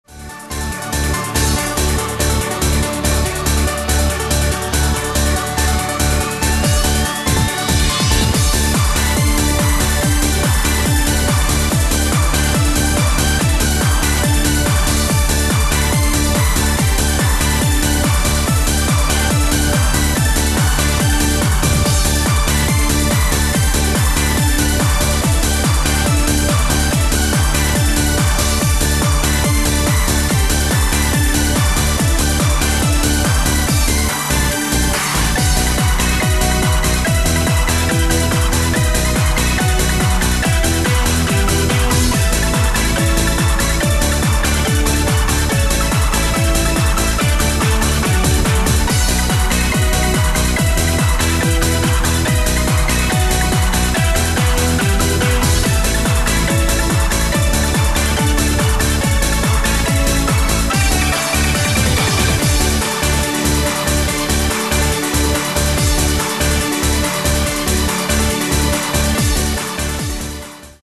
往来のファミコンVGMをドリームテクノでリメイク&アレンジ。